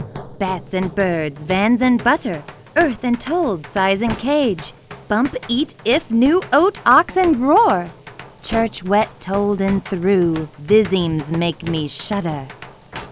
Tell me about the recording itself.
This problem deals with adaptive noise cancellation using the LMS algorithm.